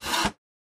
in_copingsaw_stroke_03_hpx
Coping saw cuts various pieces of wood. Tools, Hand Wood, Sawing Saw, Coping